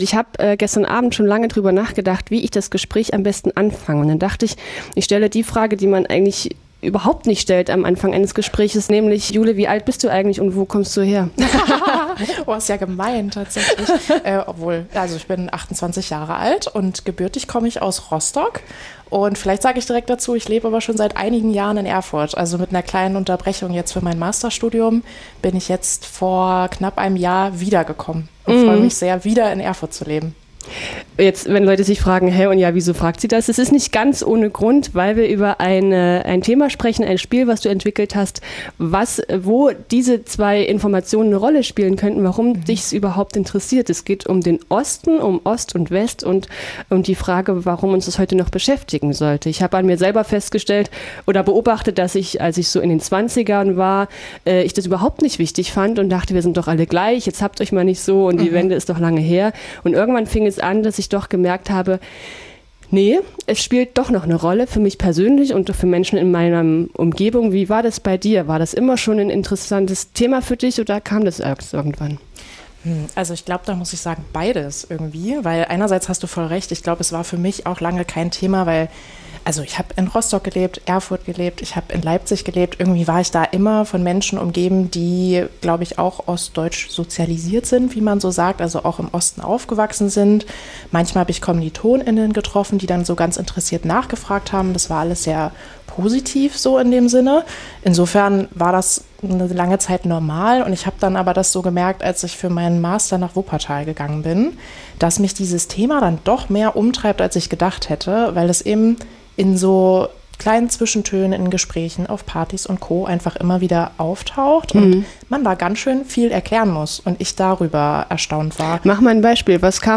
Bei Radio F.R.E.I. sprach sie darüber.